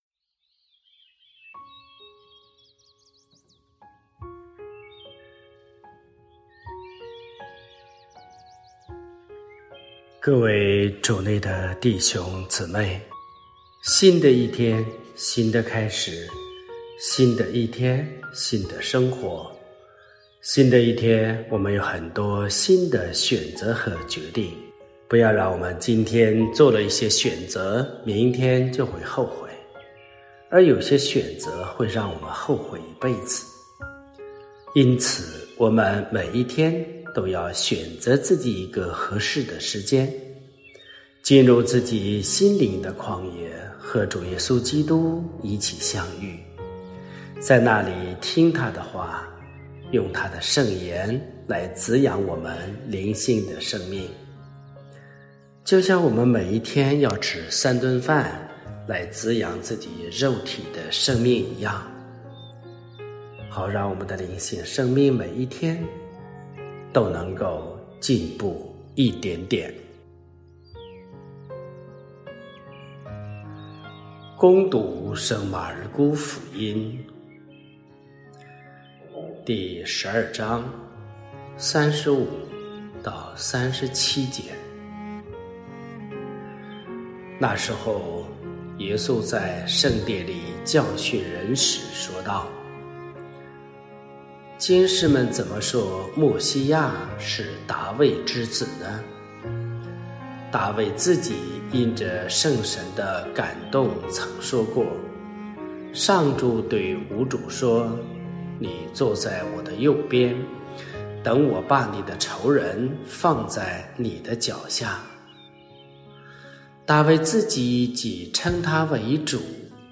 背景音乐还有声音的调制不如之前的效果